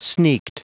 Prononciation du mot sneaked en anglais (fichier audio)
Prononciation du mot : sneaked